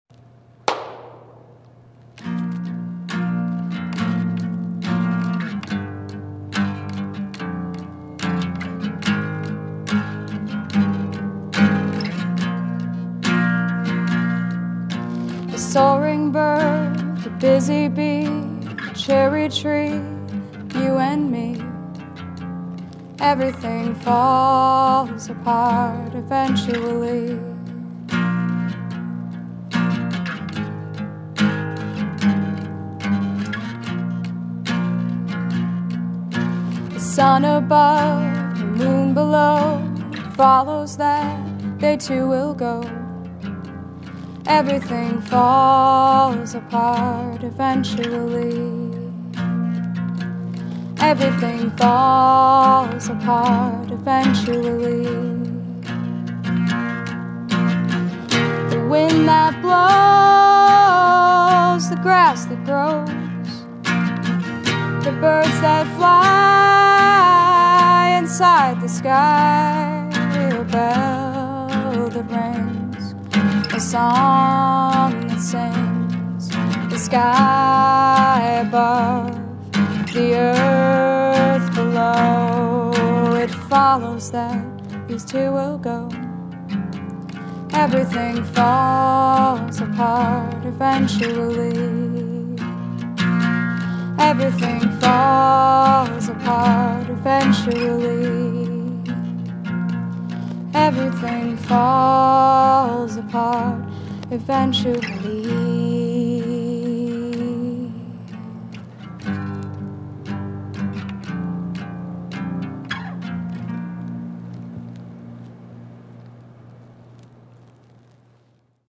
Detroit Opera House